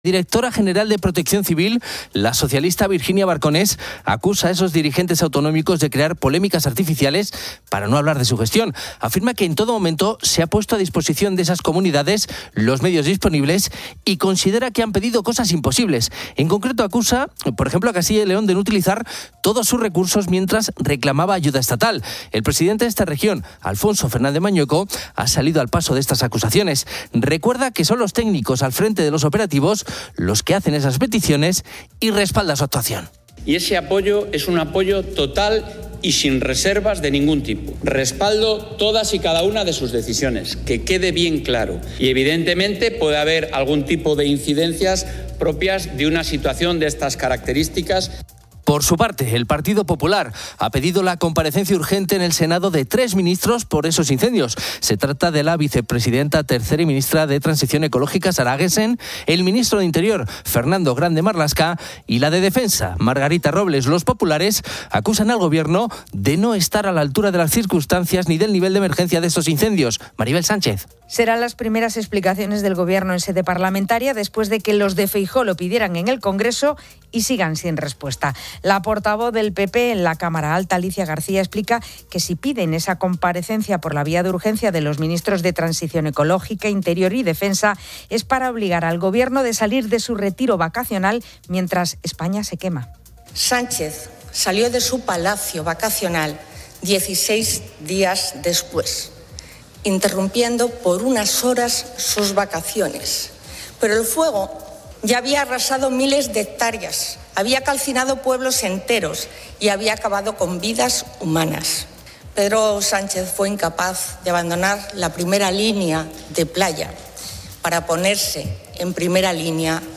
El audio aborda temas de actualidad política, económica y un segmento de radio con una entrevista personal. En política, se discuten acusaciones sobre la gestión de incendios forestales en España, con la directora de Protección Civil acusando a líderes autonómicos de crear polémicas.